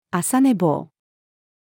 朝寝坊-female.mp3